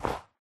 snow3.ogg